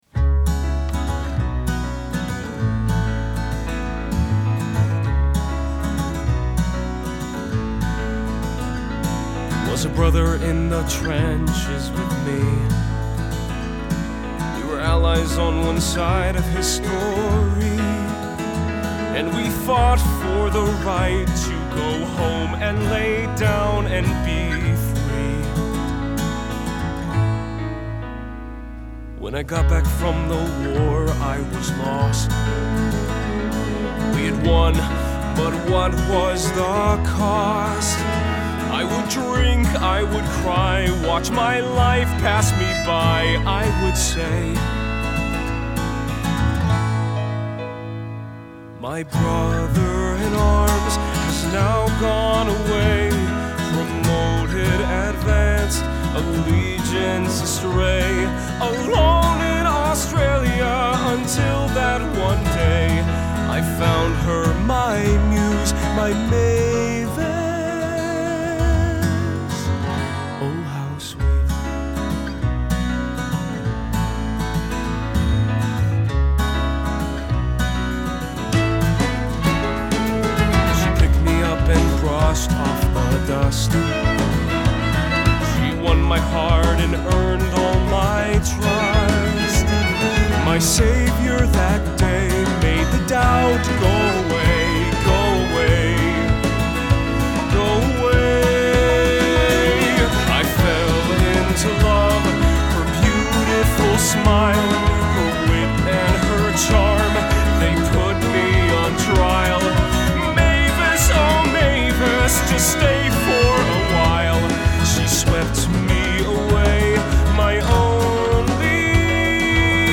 The song that begins on a reflective note quickly shifts to darker thoughts of revenge.